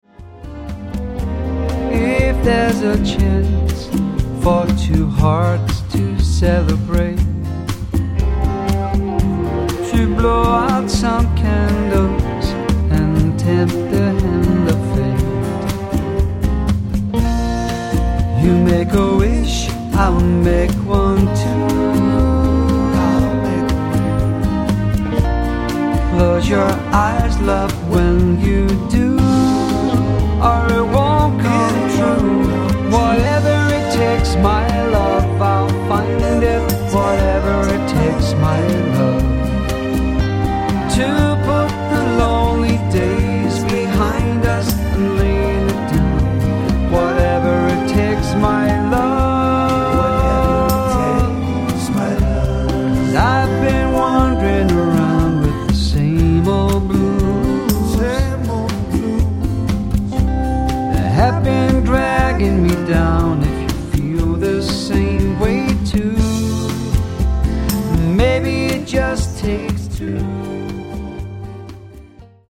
swing
Tribute Music Samples